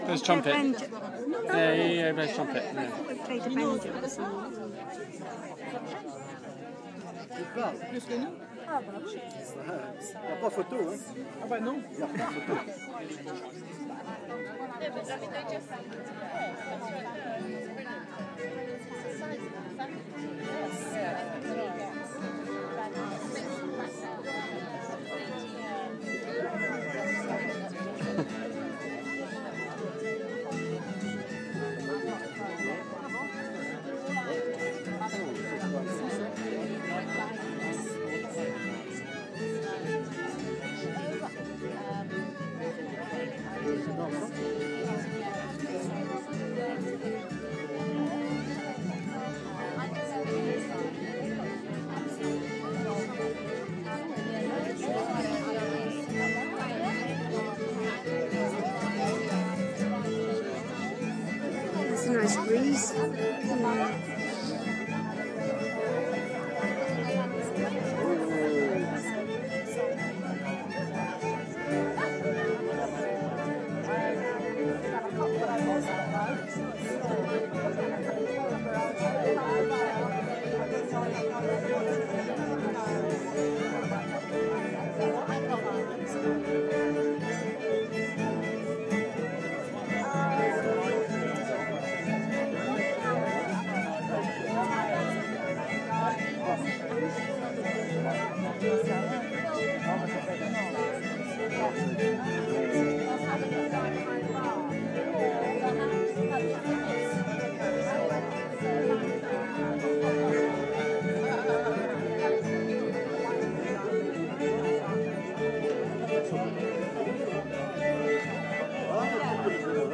Bluegrass in Pleuville